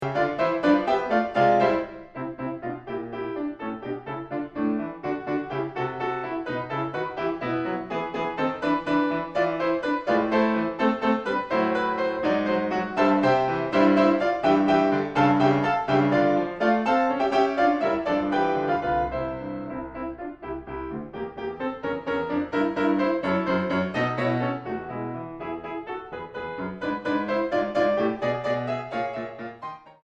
Rubato 5.17